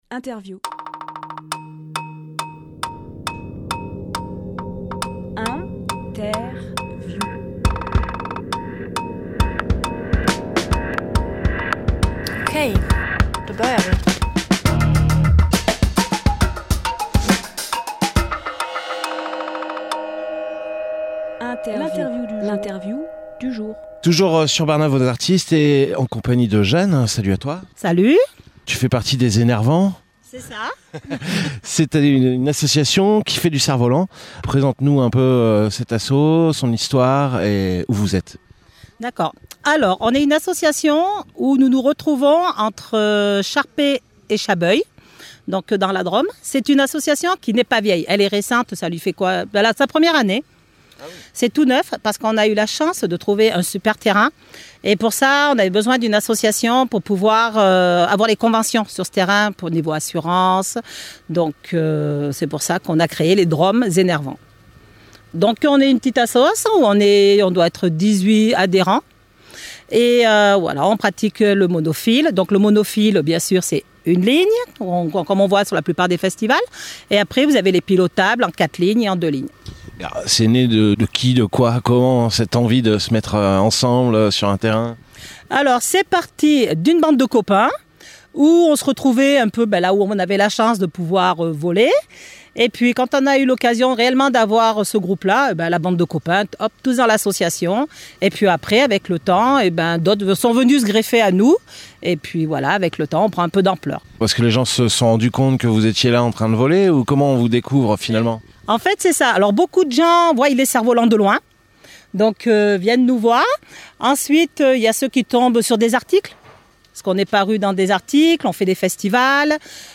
Emission - Interview Barnave Aux Artistes : Drôme Zen Air Vent Publié le 24 juin 2023 Partager sur… Télécharger en MP3 Barnave Aux Artistes, première édition d’un évènement artistique pluridisciplinaire à l’échelle du village de Barnave.
Lieu : Barnave